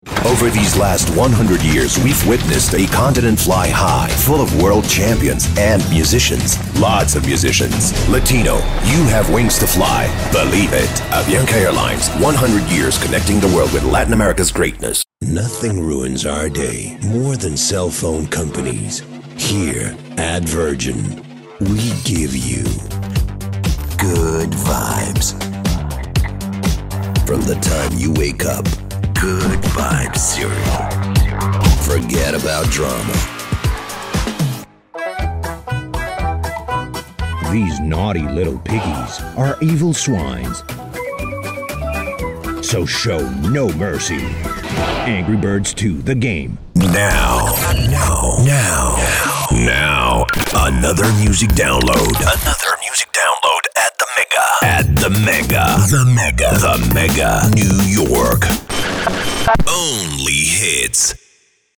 room tone.mp3
Audio equipment: professional sound proof studio